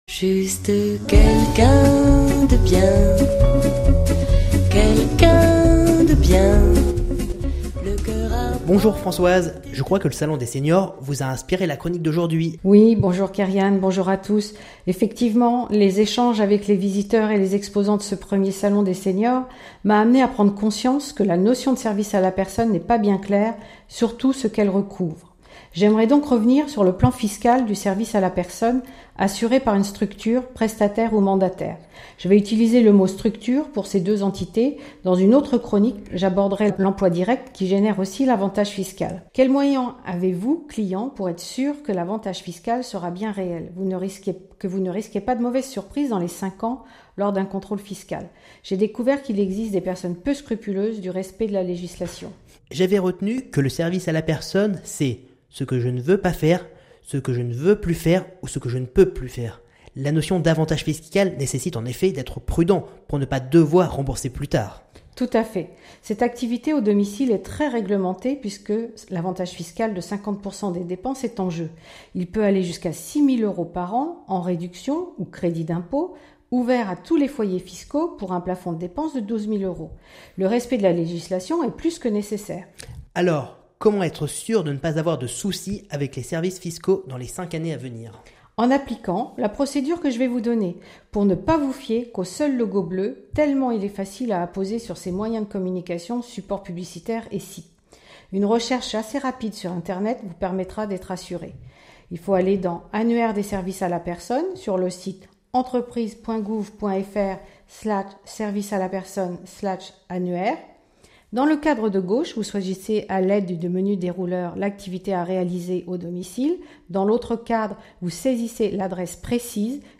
Chronique radio – Services À la Personne – Comment s’assurer de la qualité de Service à la Personne